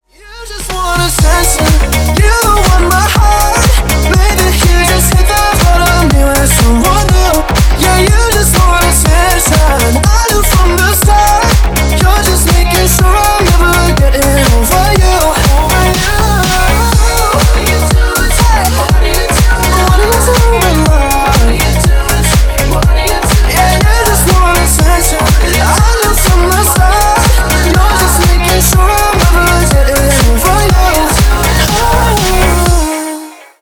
• Качество: 320, Stereo
мужской голос
Electronic
EDM
Club House